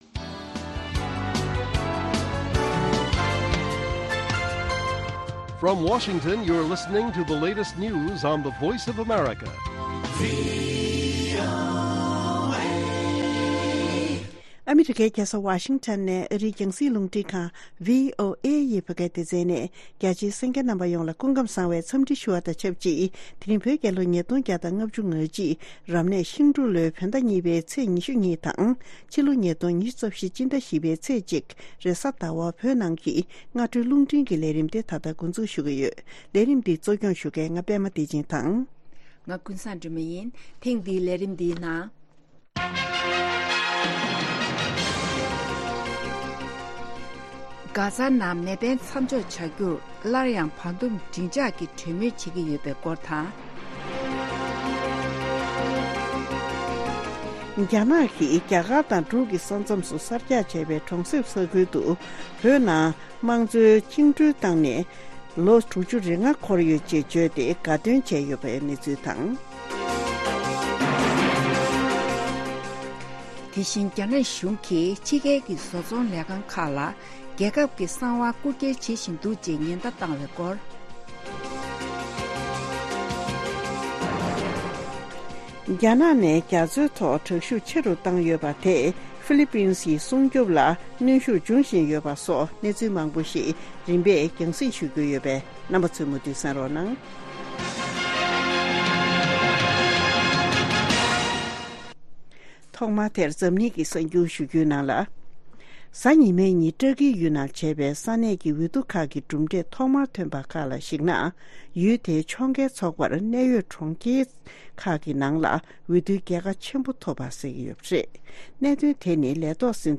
སྔ་དྲོའི་རླུང་འཕྲིན།